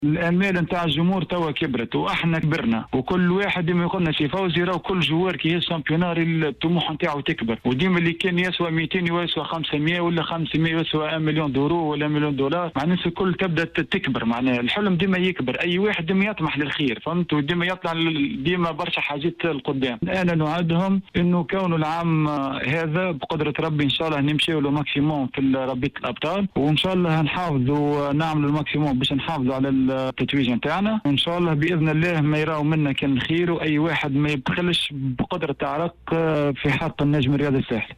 خلال استضافته في بلانات سبور